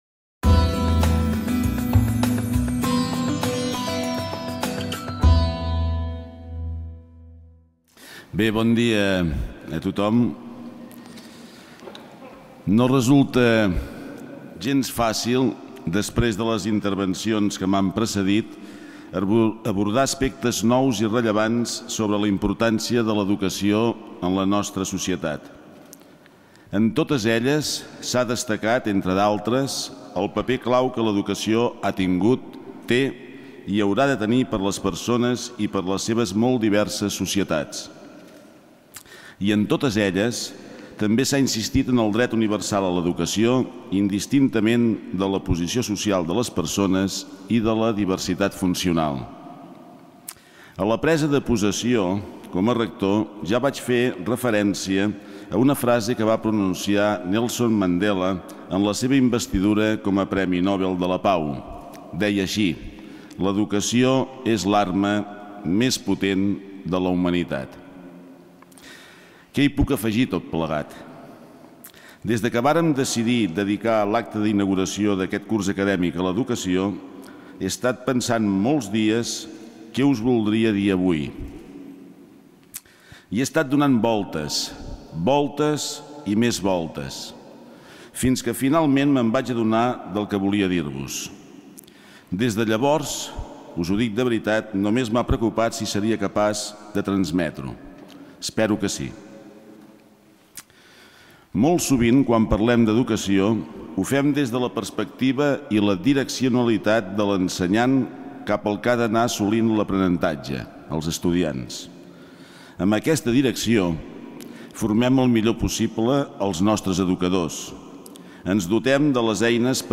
Inauguració del curs 2014-2015. Persones intel·lectualment lliures, obertes, crítiques i compromeses amb la societat - Discurs Dr Sergi Bonet, Rector UdG
Inauguració del curs 2014-2015 per part del rector de la Universitat de Girona Sergi Bonet